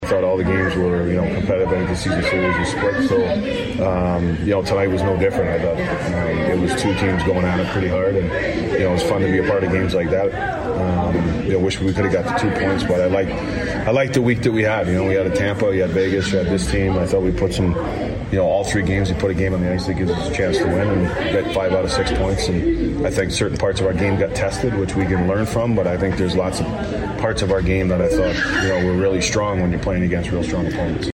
Wild coach John Hynes on the season series against Colorado and going 1-1-1 against the Avs, Knights and Lightning.